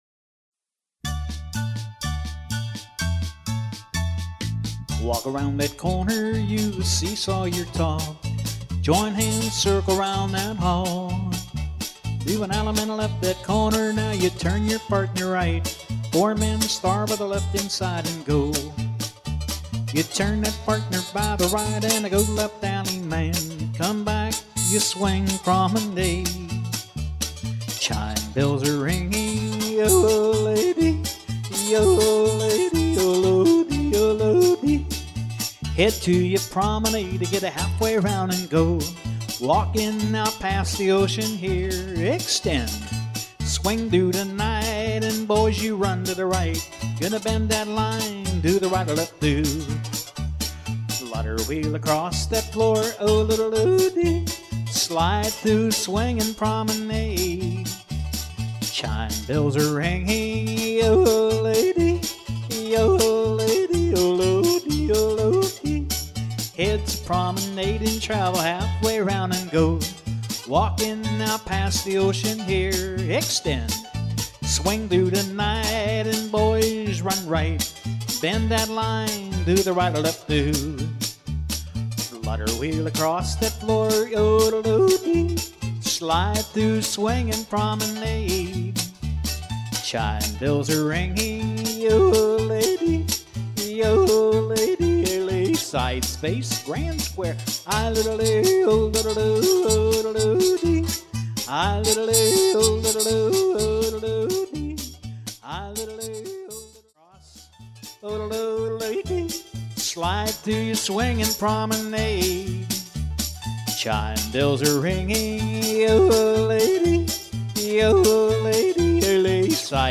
Vocal Tracks